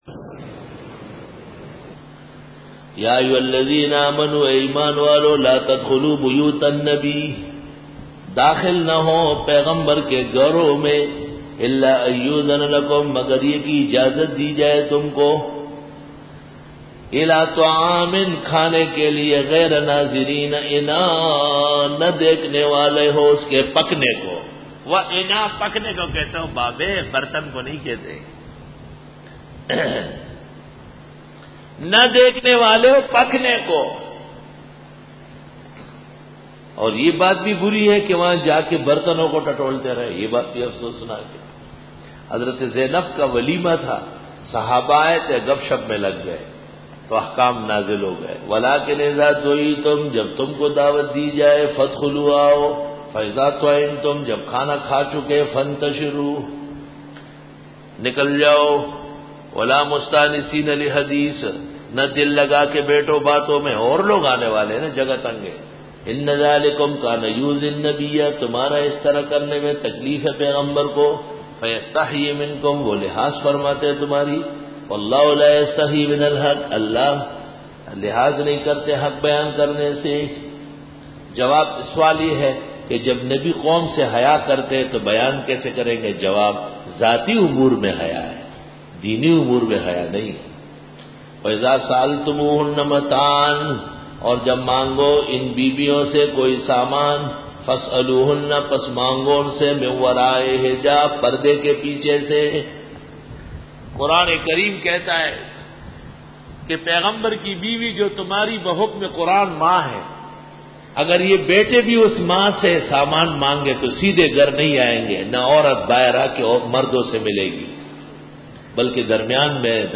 سورۃ الاحزاب رکوع-07 Bayan